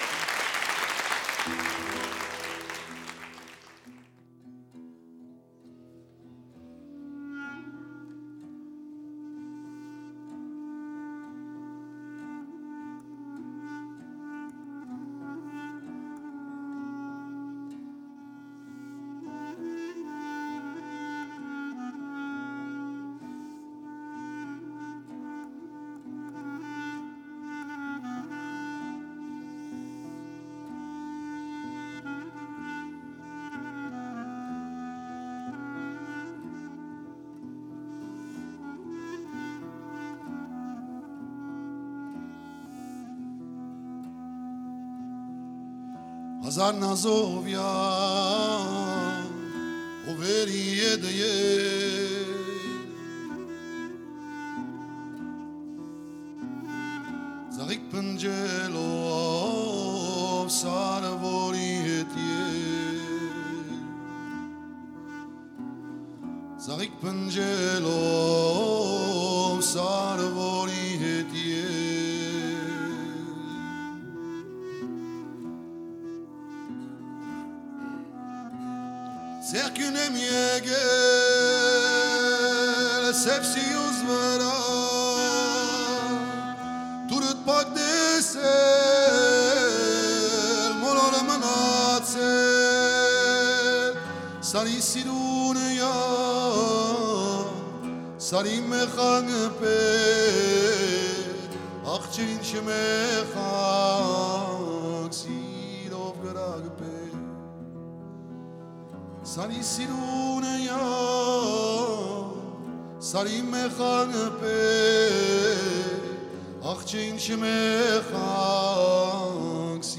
其音乐不仅延续了东欧音乐和“茨冈爵士”的神韵，
在他们的那些不同凡响、结构精巧的音乐中，
“既有吉普赛的聪敏机智，也有地中海的热烈亢奋，